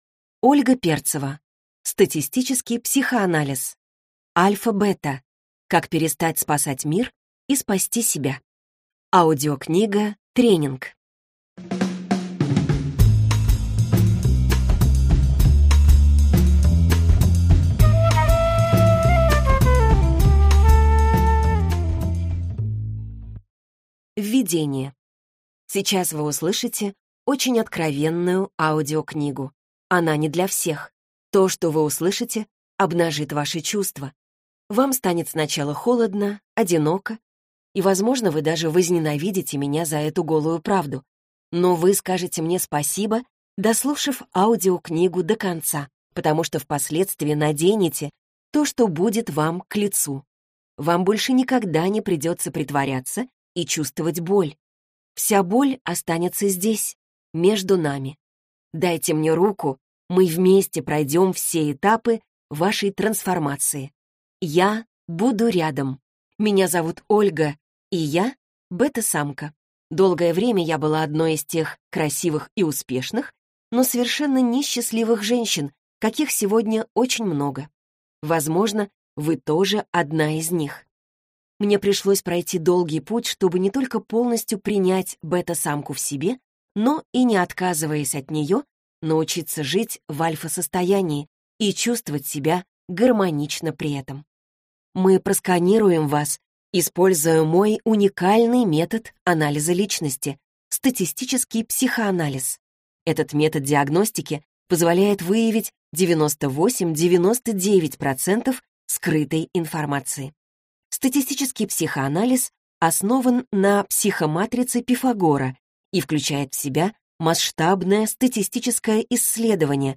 Аудиокнига АльфаБета. Как перестать спасать мир и спасти себя | Библиотека аудиокниг